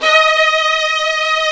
Index of /90_sSampleCDs/Roland LCDP13 String Sections/STR_Violins V/STR_Vls8 Agitato